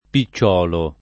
pi©©0lo] (lett. picciuolo [